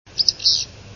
Willow Flycatcher
Aberdeen, one "fhitzbewwh" (4kb) from wave 773 with illustration below showing the remarkable number of notes in what is a high-speed trill with marked but nearly inaudible pauses, prefix and suffix